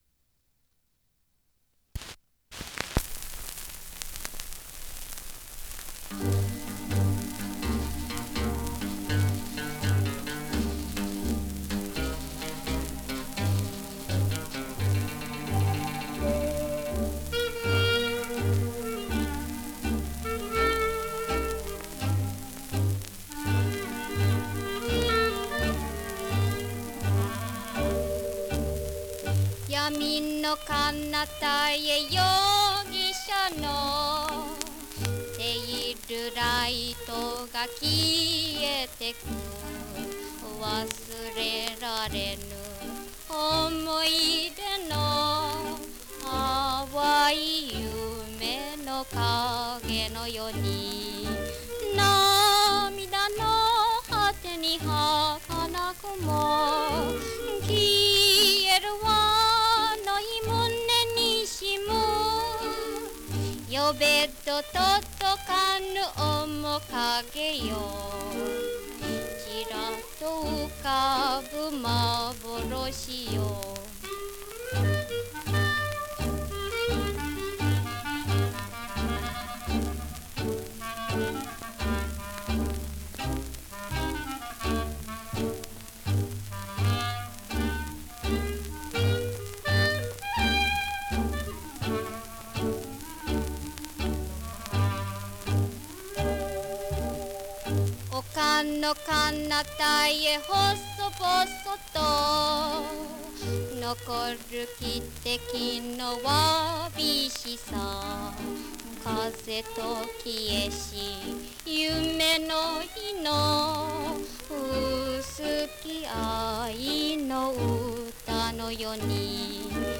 (one speaker monaural